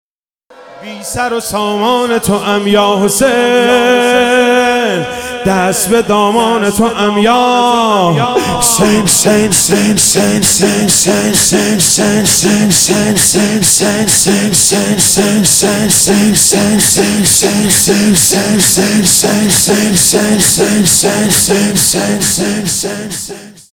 محرم99 - شب عاشورا - شور - بی سر و سامان تو ام
محرم99